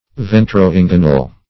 Search Result for " ventro-inguinal" : The Collaborative International Dictionary of English v.0.48: Ventro-inguinal \Ven`tro-in"gui*nal\, a. (Anat.)